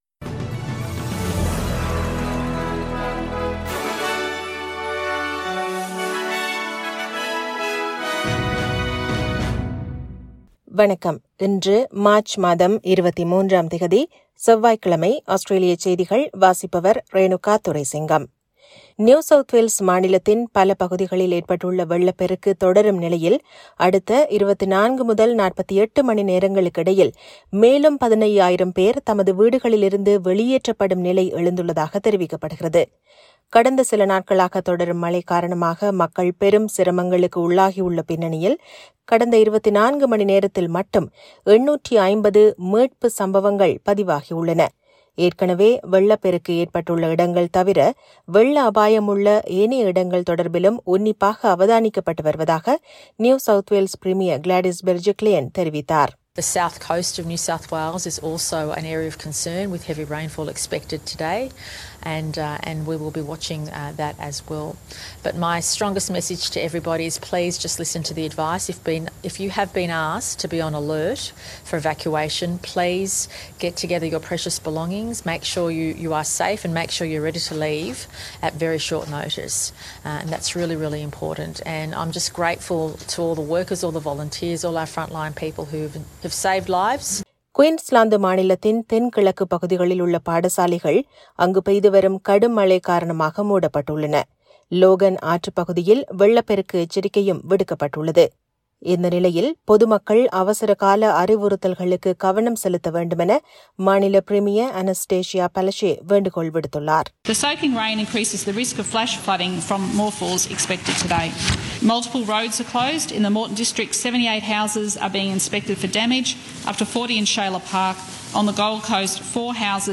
Australian news bulletin for Tuesday 23 March 2021.